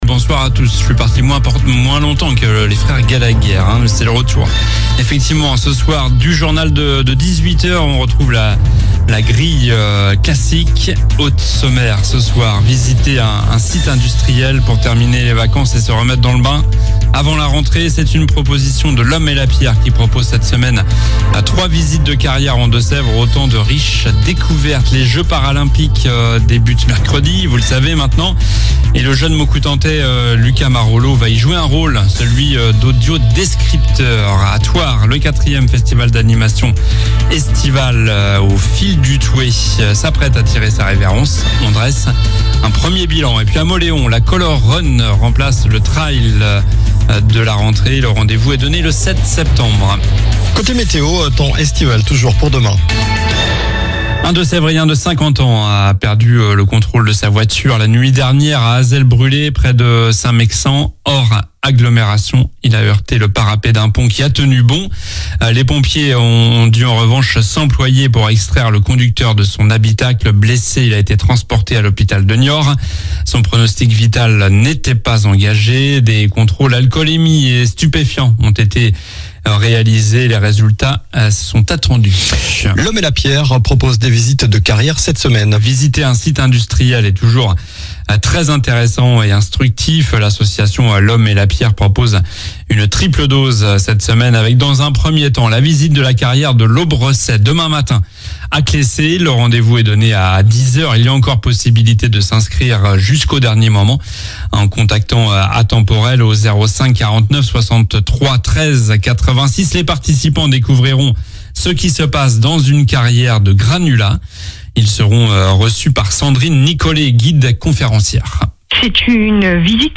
Journal du lundi 26 août (soir)